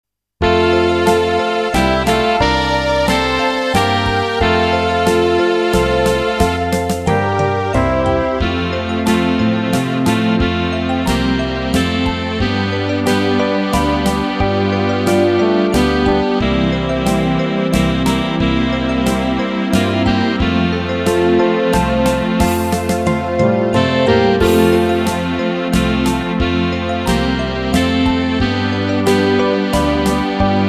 Rubrika: Pop, rock, beat
- waltz